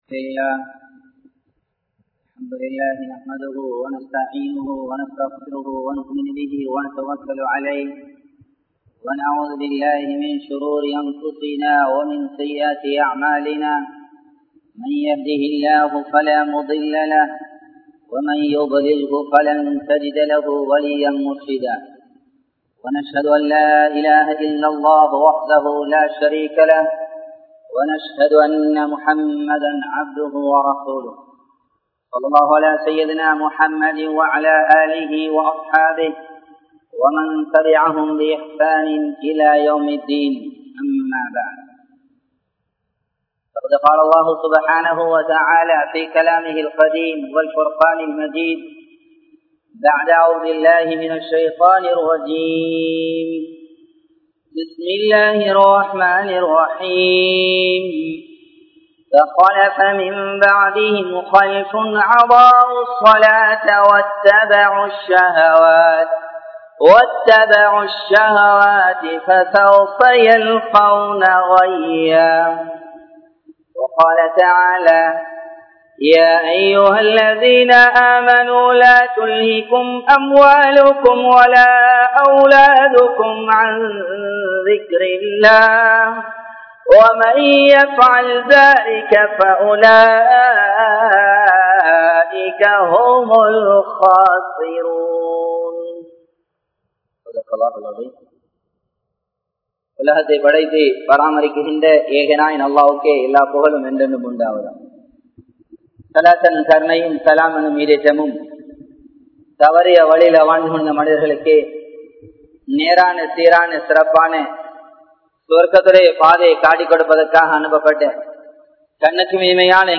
Nearam Thavari Tholufavarhal Sellum Idam Ethu? (நேரம் தவறி தொழுபவர்கள் செல்லும் இடம் எது?) | Audio Bayans | All Ceylon Muslim Youth Community | Addalaichenai
Colombo 12, Aluthkade, Muhiyadeen Jumua Masjidh